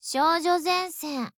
File:M249SAW TITLECALL JP.ogg